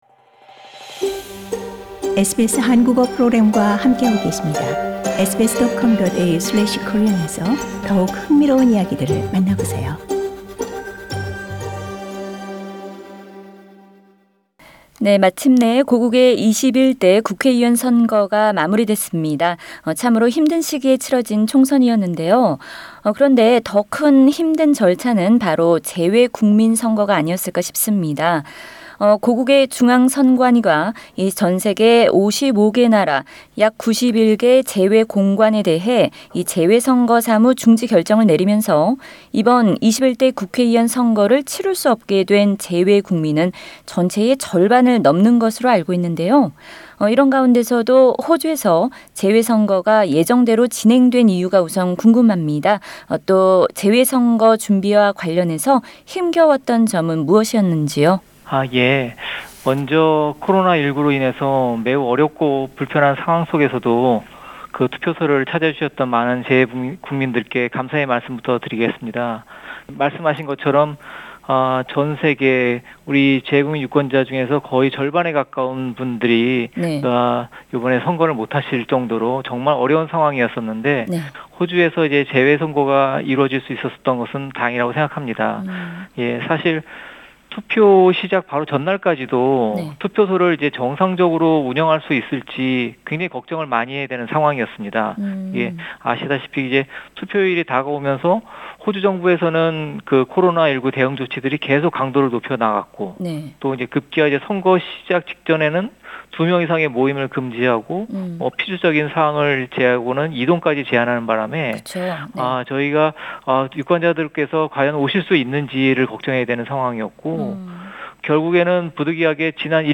SBS Radio Korean Program conducted an interview with Mr Sangwoo Hong, Consul General of the Republic of Korea in Sydney, about the overseas voting held in Australia.